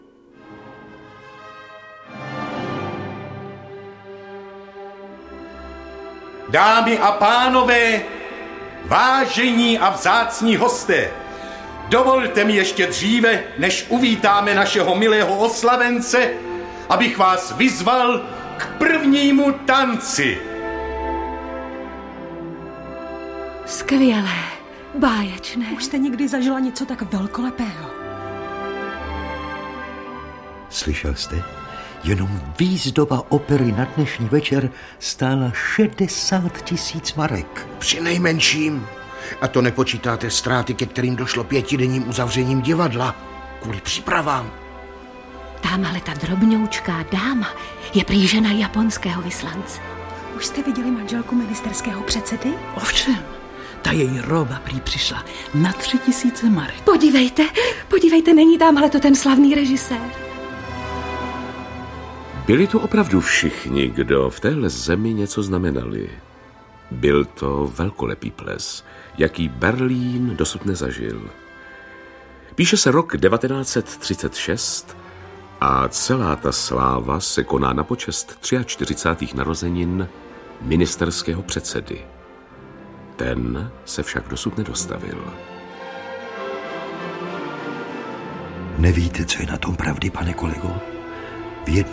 Audiokniha
Čte: Různí interpreti